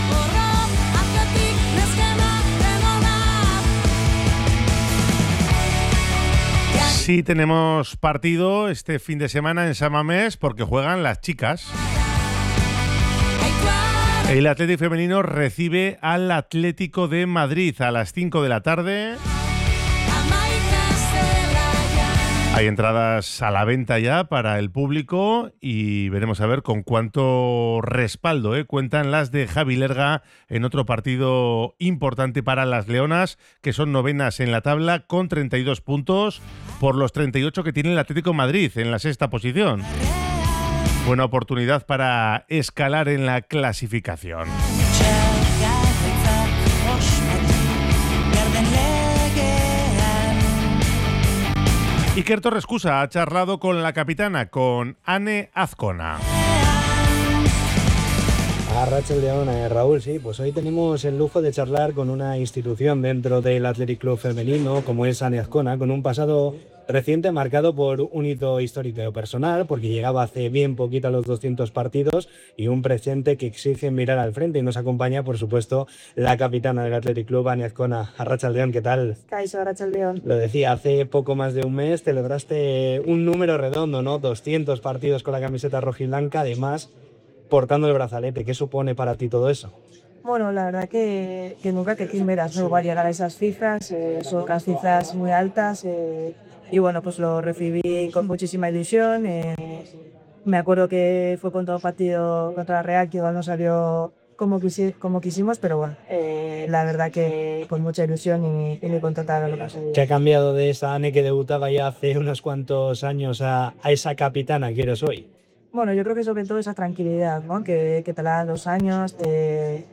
Entrevista con la capitana del Athletic Club